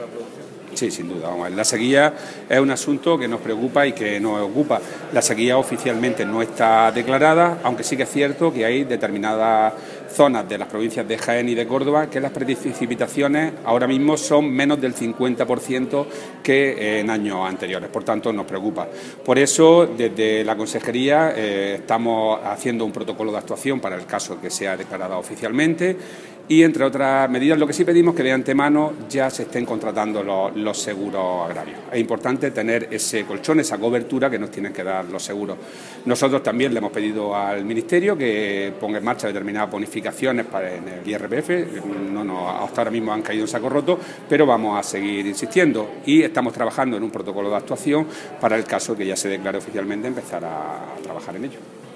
Declaraciones de Rodrigo Sánchez sobre sequía